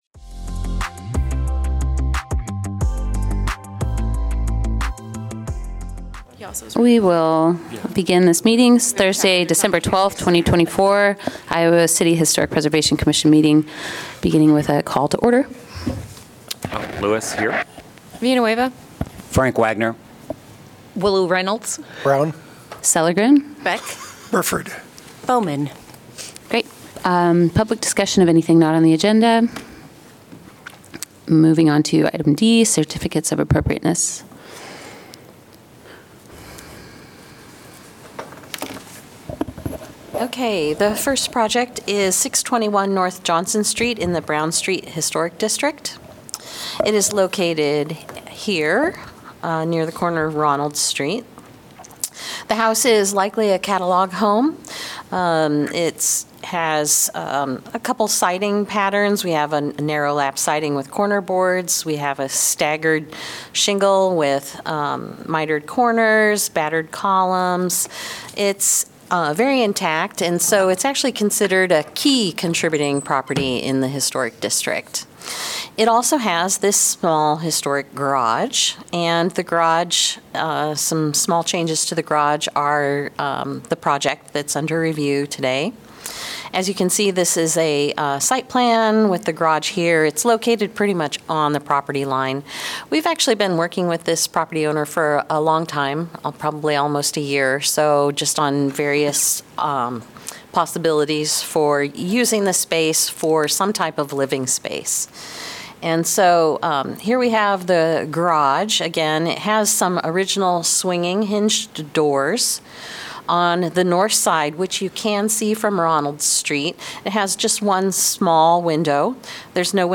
Regular meeting of the Iowa City Historic Preservation Commission.